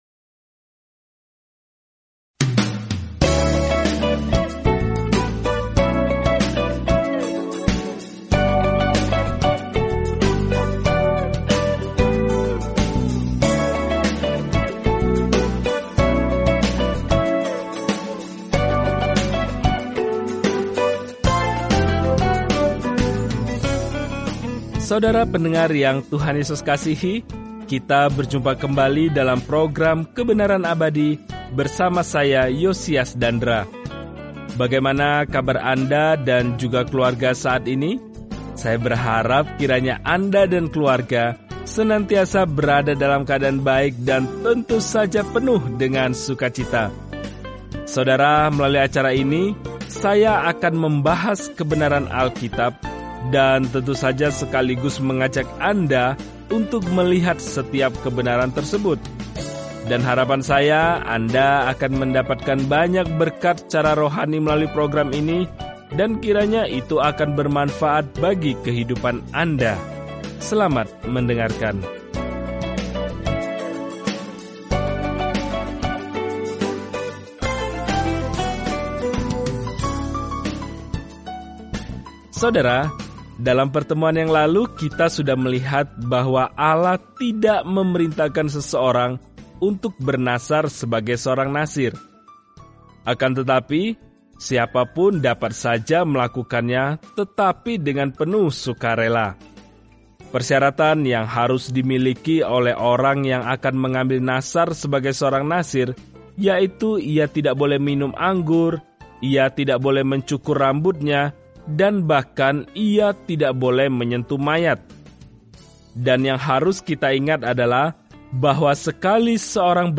Firman Tuhan, Alkitab Bilangan 6:8-27 Bilangan 7 Hari 4 Mulai Rencana ini Hari 6 Tentang Rencana ini Dalam kitab Bilangan, kita berjalan, mengembara, dan beribadah bersama Israel selama 40 tahun di padang gurun. Jelajahi Numbers setiap hari sambil mendengarkan studi audio dan membaca ayat-ayat tertentu dari firman Tuhan.